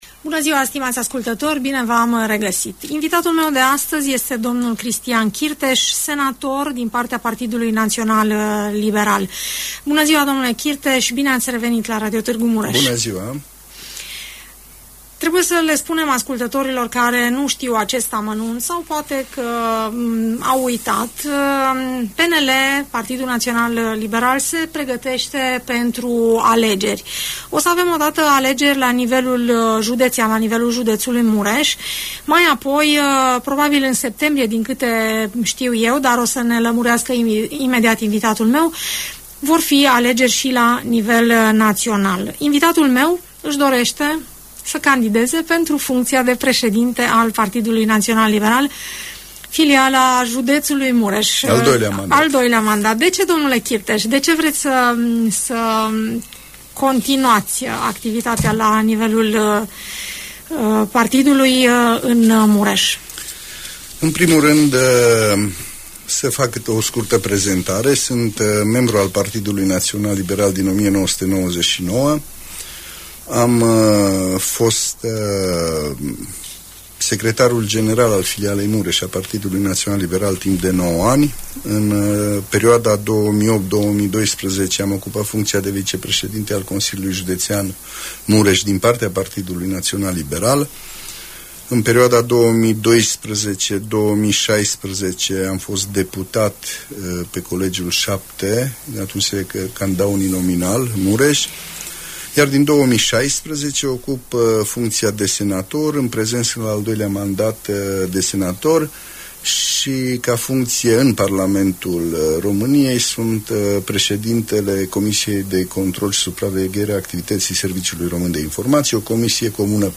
Senatorul Cristian Chirteș candidează pentru un nou mandat de șef al PNL Mureș. Domnia sa, schițează la Radio Tg Mureș, câteva intenții și proiecte, cu care se prezintă în fața alegătorilor care își vor alege liderul în data de 7 august 2021 .